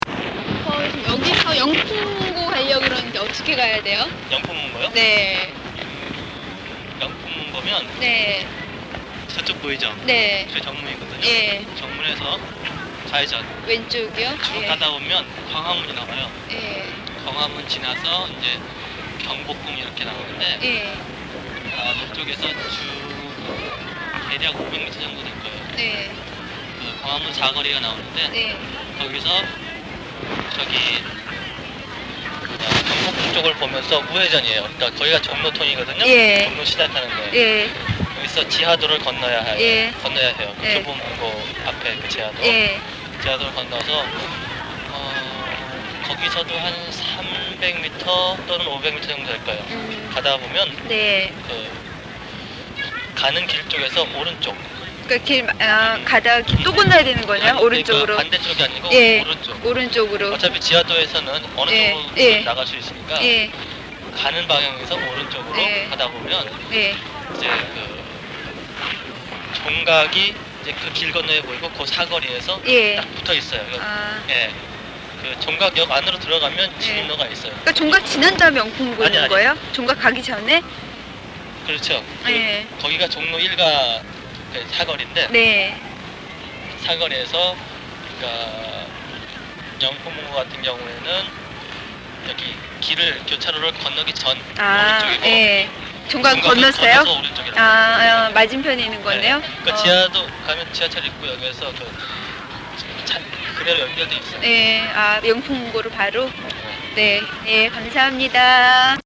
RN37track02_ Realconversation_1_for_PT4.mp3 (2.5 MB)